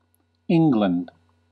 Pronunciation: / ˈ ɪ ŋ ɡ l ən d /
En-us-England.ogg.mp3